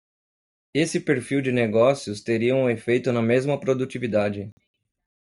Pronounced as (IPA)
/pɾo.du.t͡ʃi.viˈda.d͡ʒi/